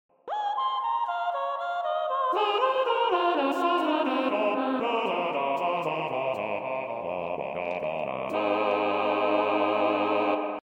Blob Opera